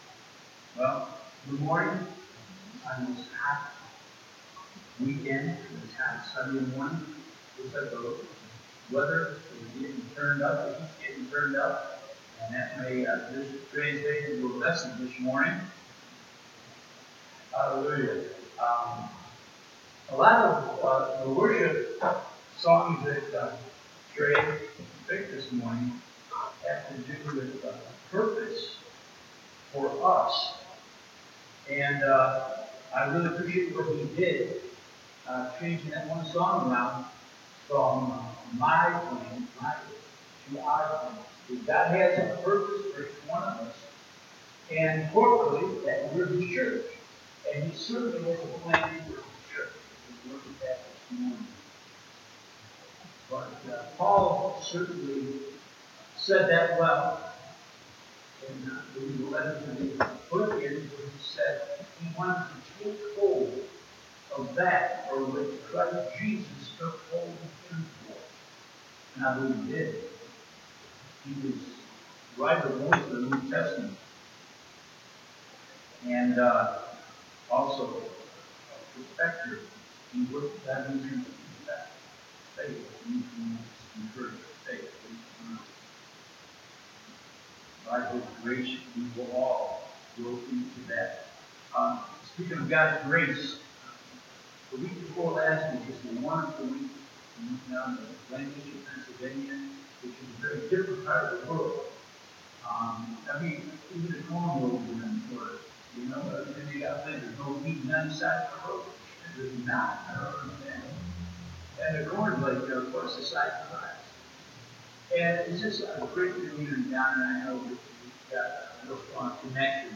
You might notice a lack of sound quality on this sermon.
Romans 7&8 Service Type: Sunday Morning You might notice a lack of sound quality on this sermon.